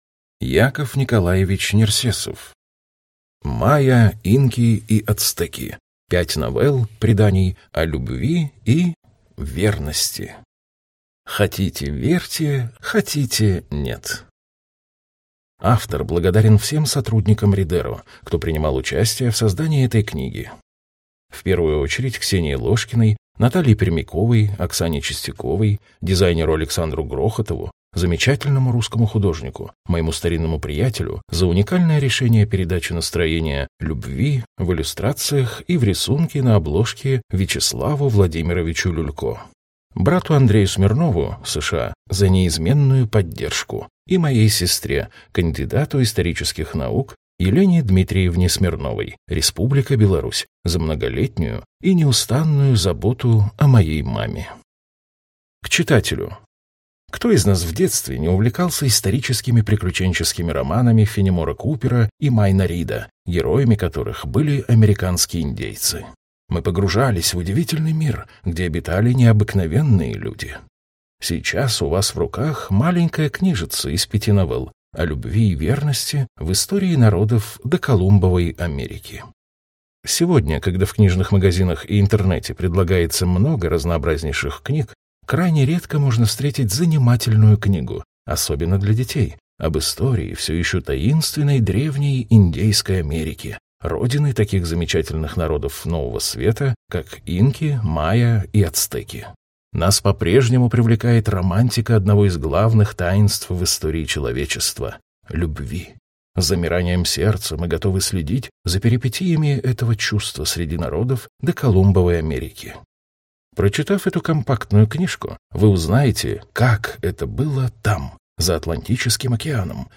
Аудиокнига Майя, инки и ацтеки. Пять новелл (преданий) о любви и… Верности. Хотите – верьте, хотите – нет | Библиотека аудиокниг